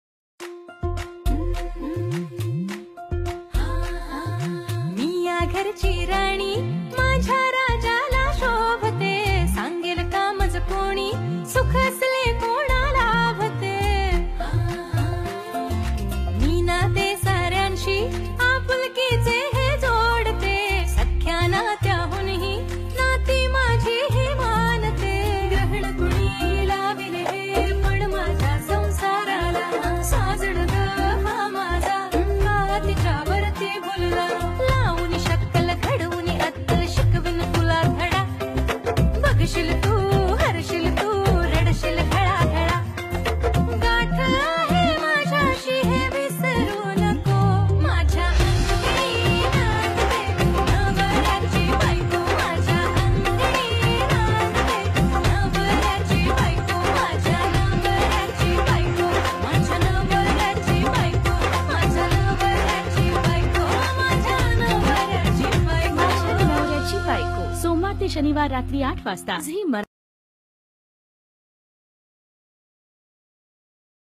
Marathi Tv Serial Song